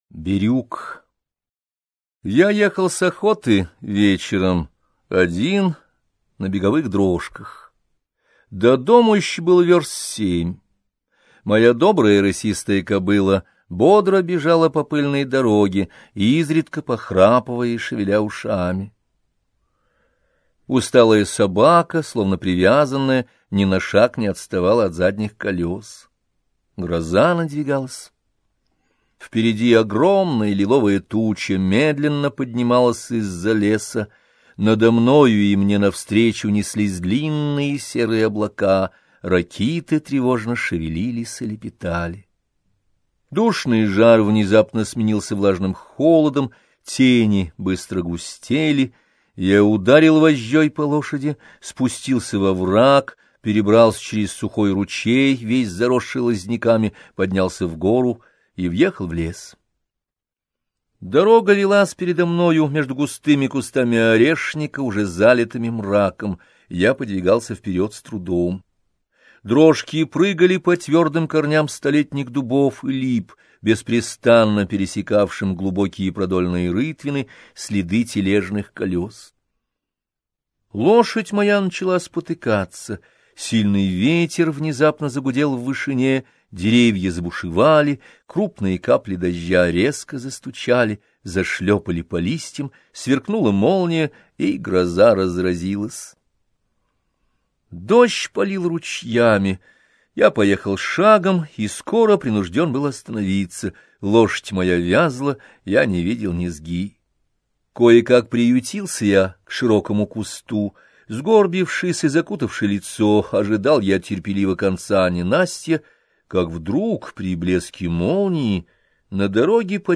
Аудиокнига Записки охотника - купить, скачать и слушать онлайн | КнигоПоиск